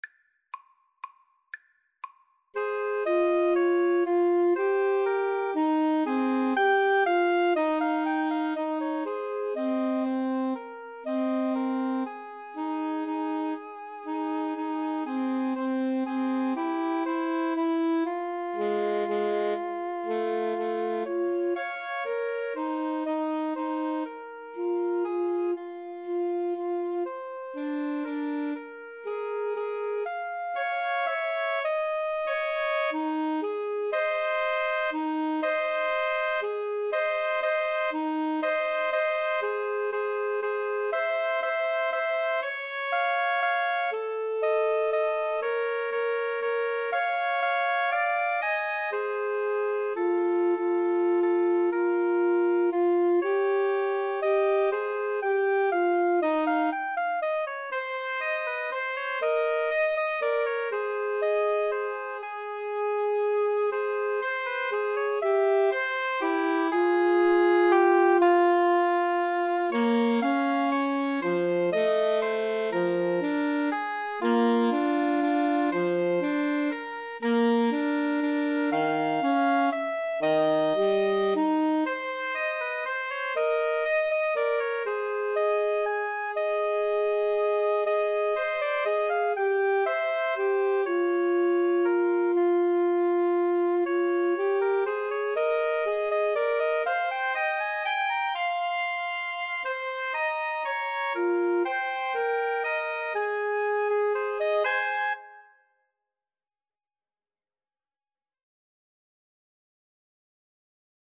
= 120 Tempo di Valse = c. 120